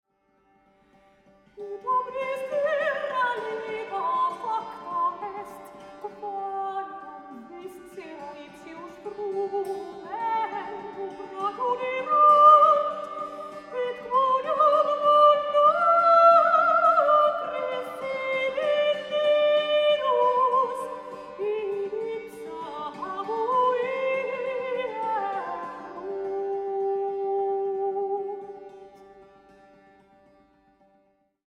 sopraano, dulcimer, sinfonia ja 5-kielinen kantele